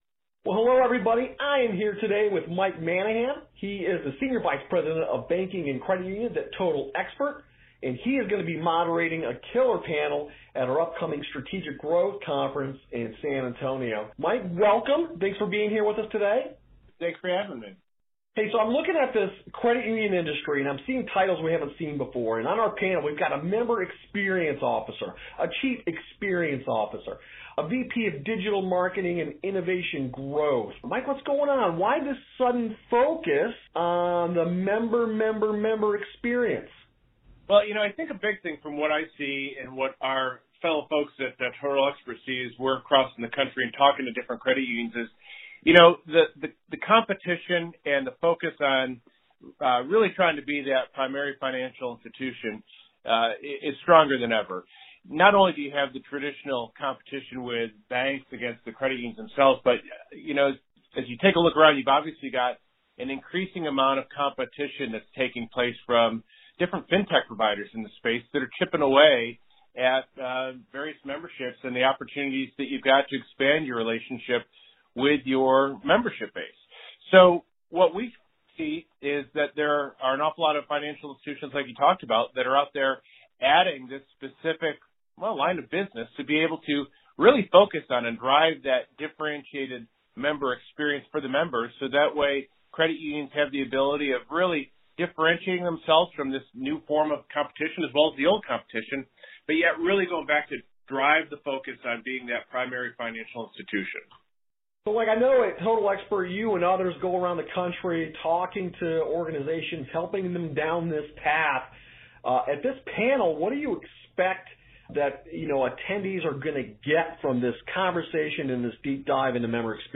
Interview transcript available below.